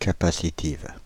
Ääntäminen
Ääntäminen Paris: IPA: [ka.pa.si.tiv] Haettu sana löytyi näillä lähdekielillä: ranska Käännöksiä ei löytynyt valitulle kohdekielelle.